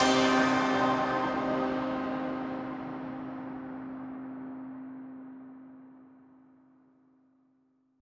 FX [ Hit ].wav